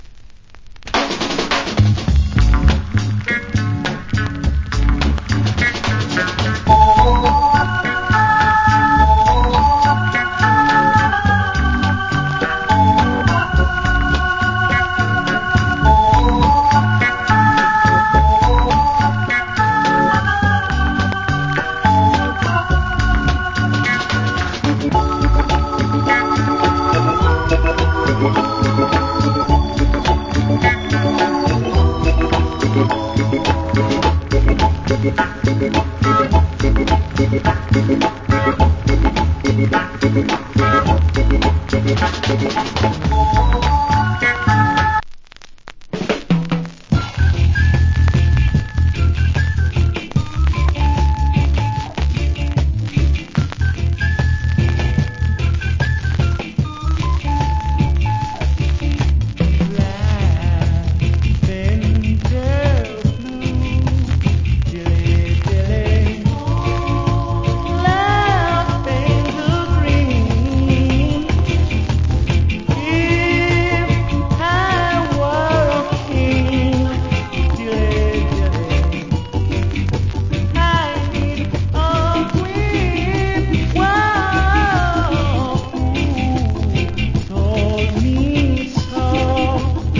コメント Great Early Reggae Vocal. / Nice Early Reggae Inst.